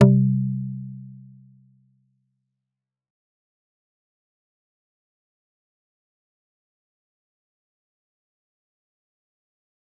G_Kalimba-C3-f.wav